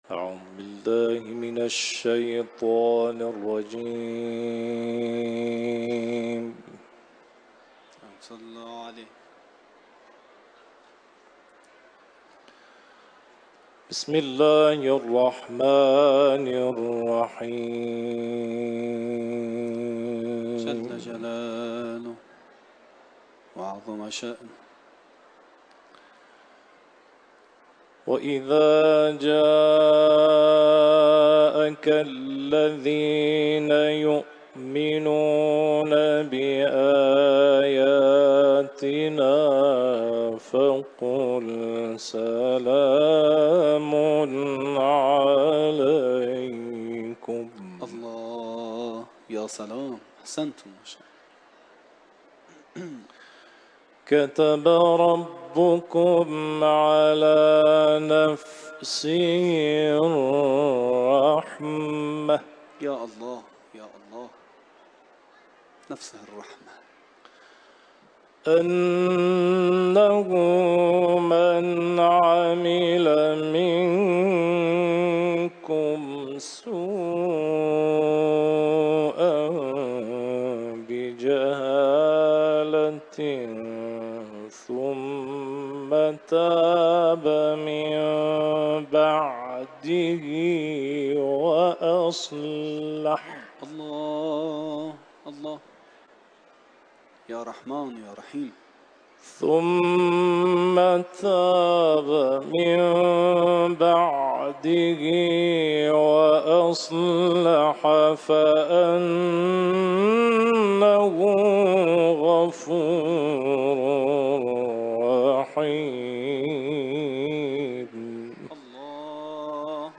تلاوت آیات 54 تا 58 از سوره مبارکه «انعام» و آیات سوره «کوثر»
تلاوت قرآن